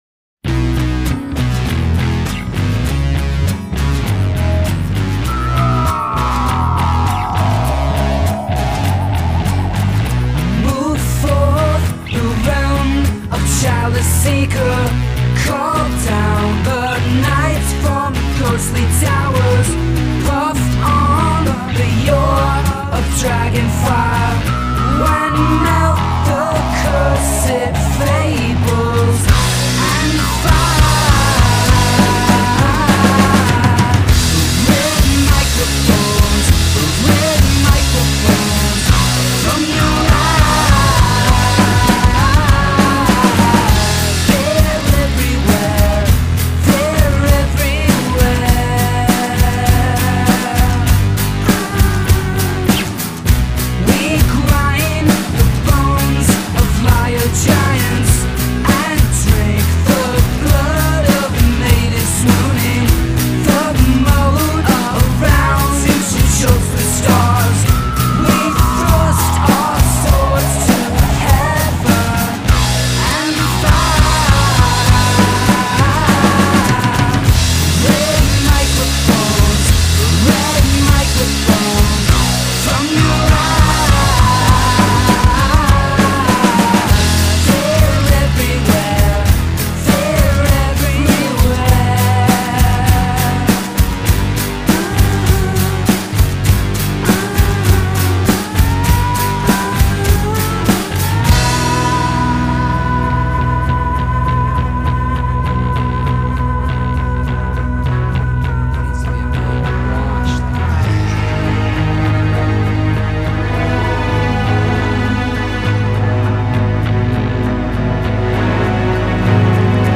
tight, well-crafted indie pop